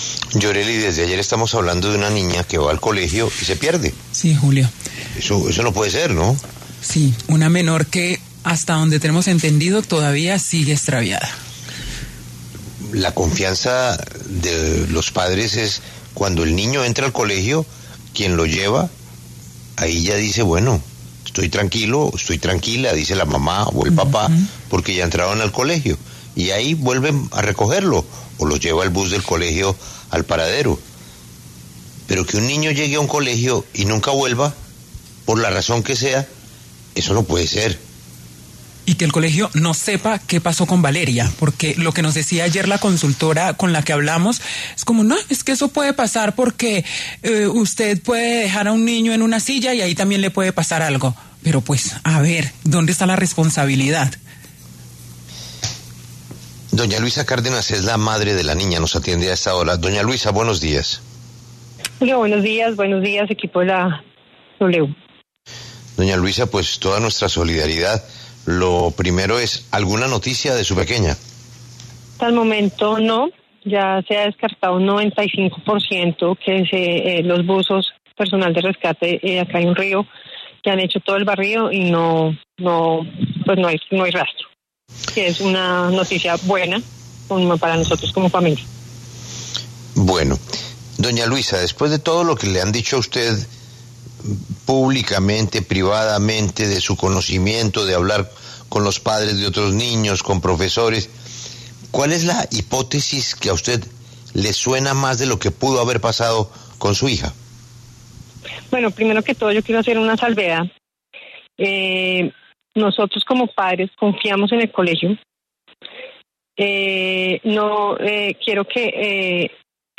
En diálogo con Julio Sánchez Cristo en La W